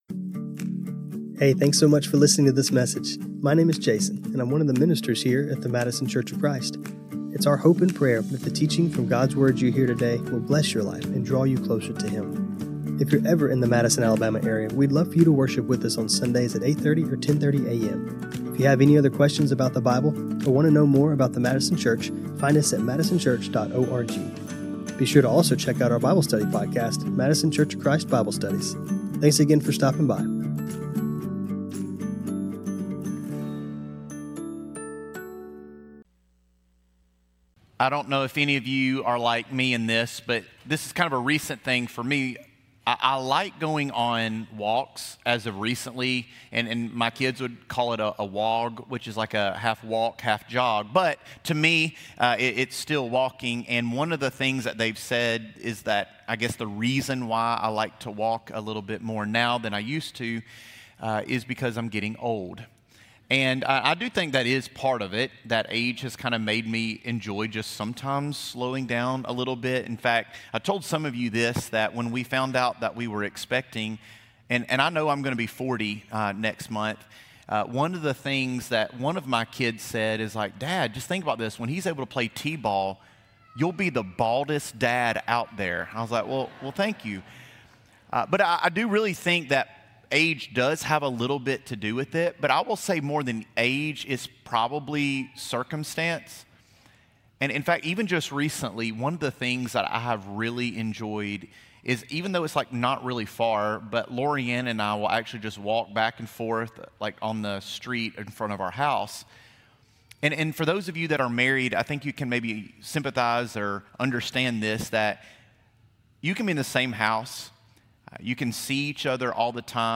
This sermon was recorded on Apr 5, 2026.